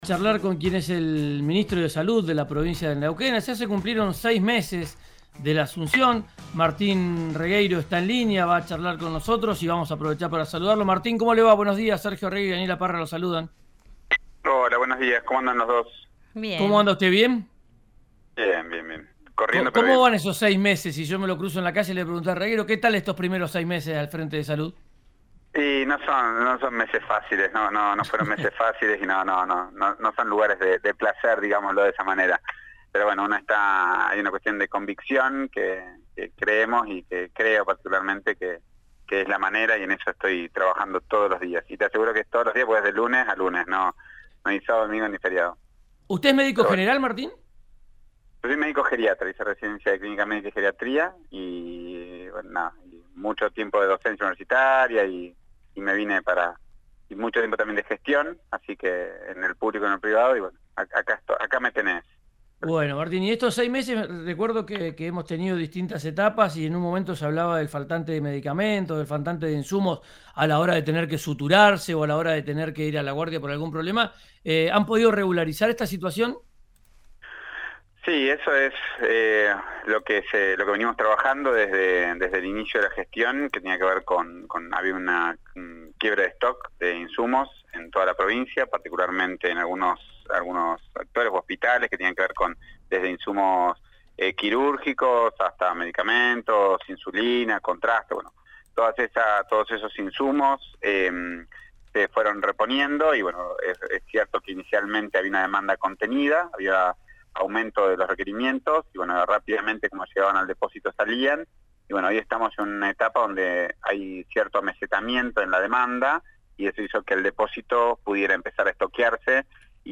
El ministro de Salud habló con RÍO NEGRO RADIO.
En las últimas semanas aumentaron los contagios de gripe A. Hospitales y centros de salud del sistema público de Neuquén reforzaron sus equipos en las guardias para hacer frente al aumento de contagios. Martin Regueiro, ministro de Salud de la provincia habló con RÍO NEGRO RADIO y dio detalles de la situación que se atraviesa.